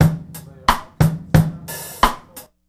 Vinnyrim 89bpm.wav